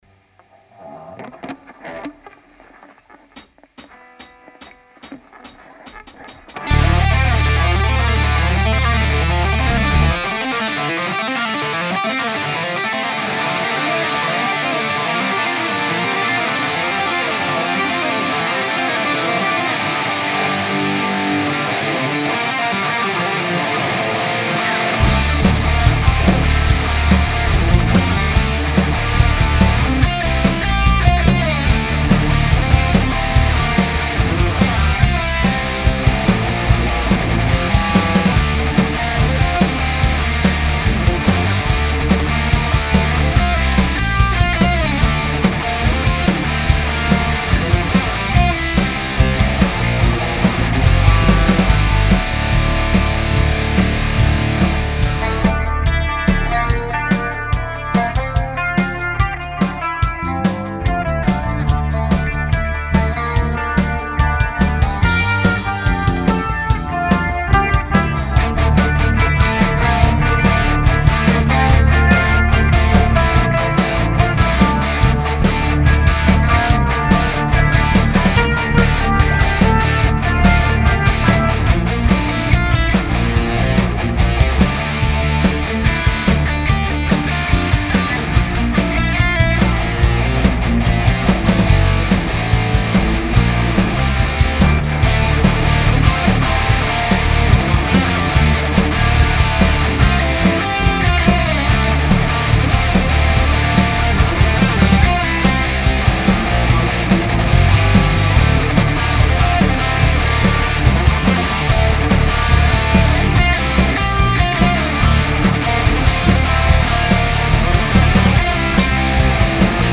Bisserl was Pink Floyd-mäßiges.